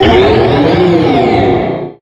Cri de Méga-Mewtwo X dans Pokémon HOME.
Cri_0150_Méga_X_HOME.ogg